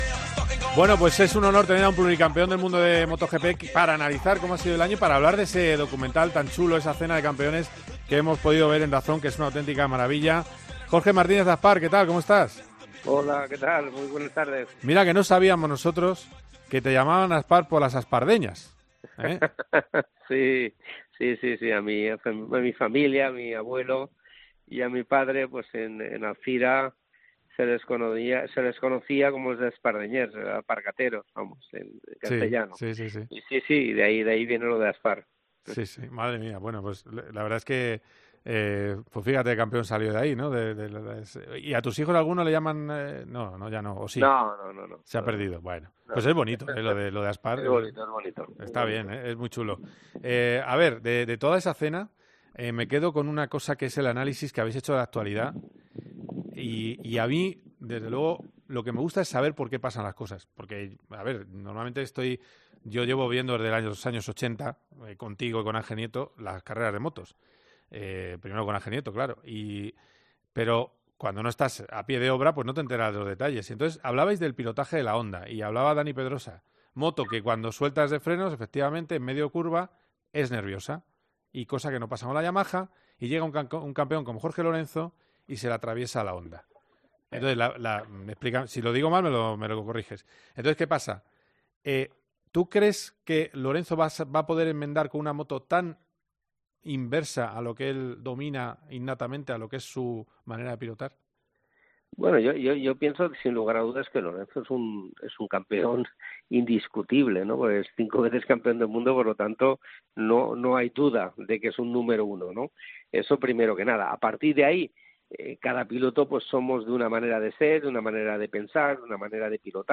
AUDIO: Entrevistamos al pluricampeón del mundo de MotoGP, Jorge Martínez Aspar para analizar cómo está transcurriendo el campeonato en su ecuador.